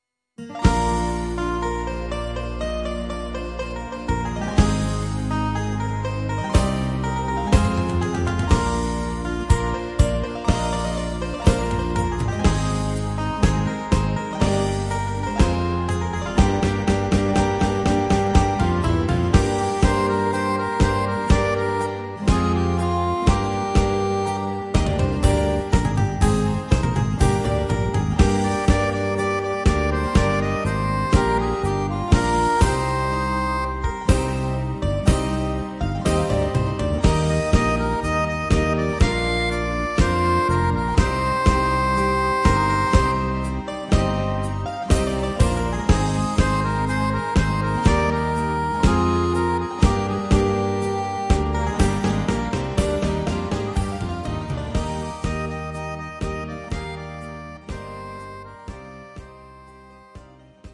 Greek Zeimpekiko